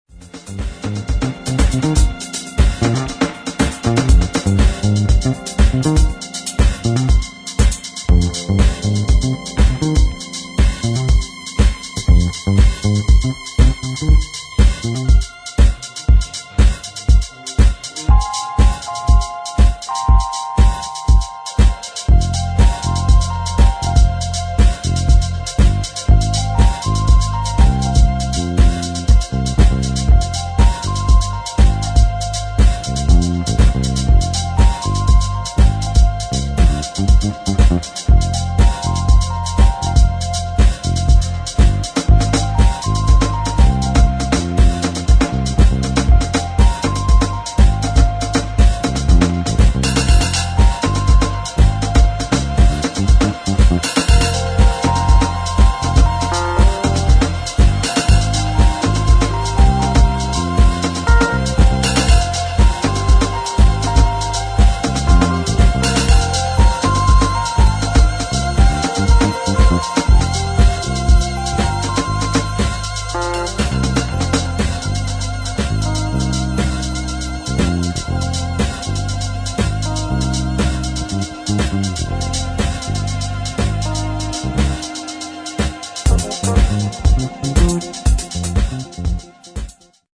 [ DEEP HOUSE / JAZZ / CROSSOVER ]